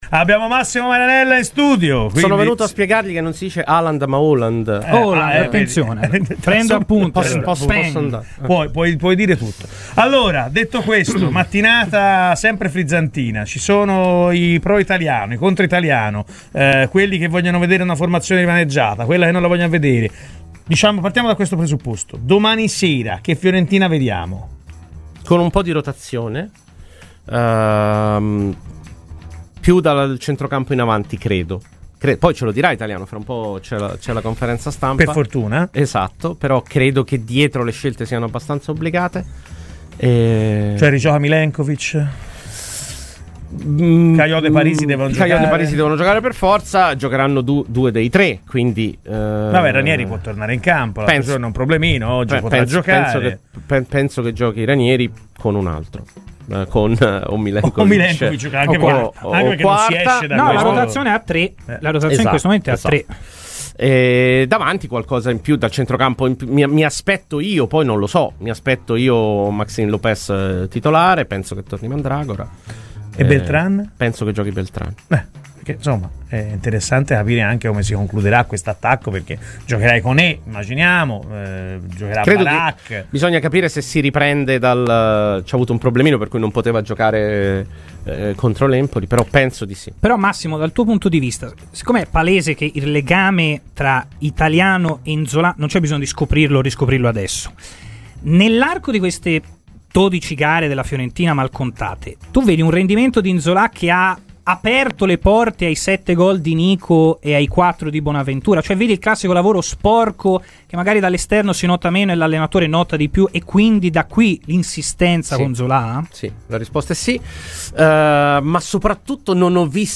Nella mattinata di Radio FirenzeViola ospite anche Massimo Marianella, storica voce di Skysport, che ha analizzato il tour de force che attende la Fiorentina ed il momento che sta vivendo M'bala Nzola: "Le partite di Conference contro il Cukaricki vanno vinte entrambe per ridare dimensione europea.